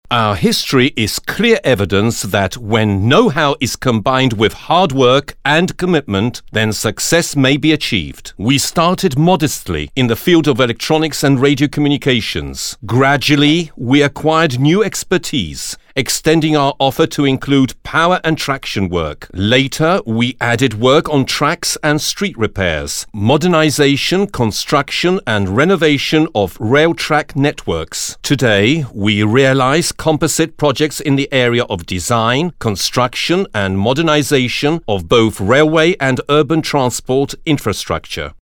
LEKTORZY VOICE – FiNE ART FiLM
NEATIVE SPEAKERS
ENG British